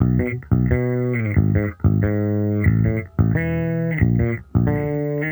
Index of /musicradar/sampled-funk-soul-samples/90bpm/Bass
SSF_JBassProc2_90B.wav